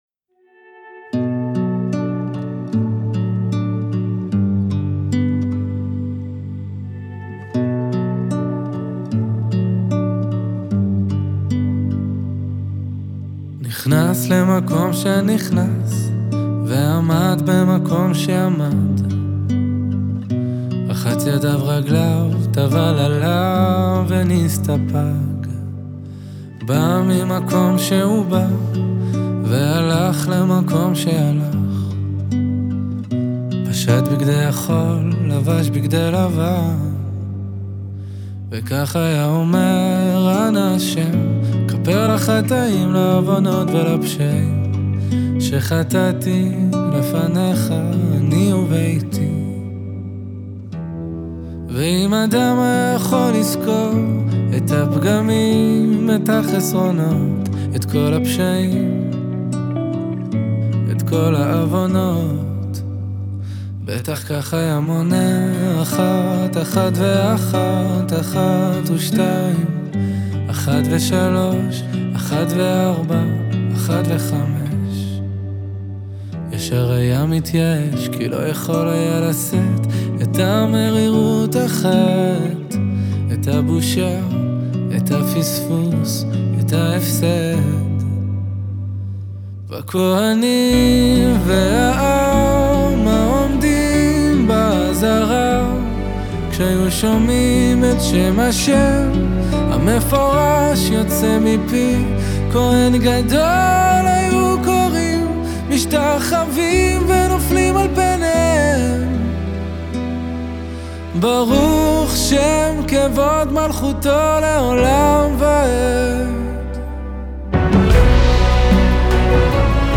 בהפקה תזמורתית מרשימה, עם כלי מיתר ומקהלה גדולה
כלי מיתר וכלי נשיפה